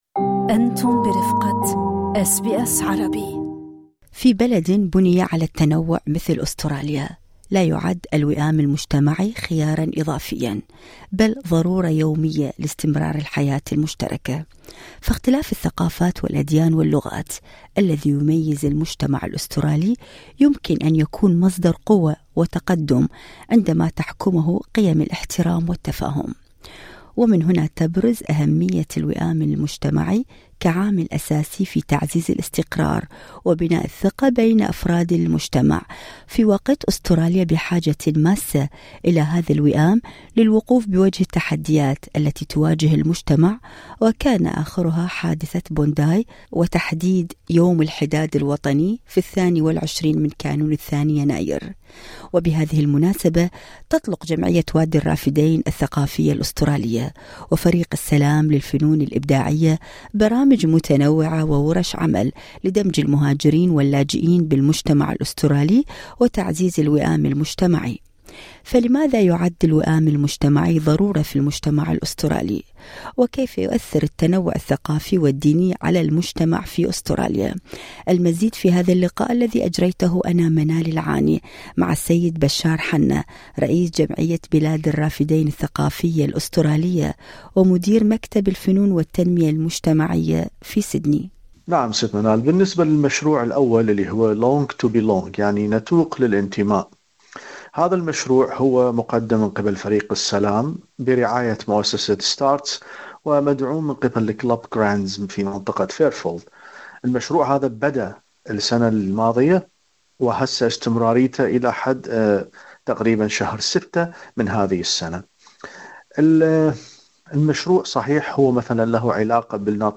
للاستماع إلى أحدث التقارير الصوتية والبودكاست، اضغطوا على الرابط التالي.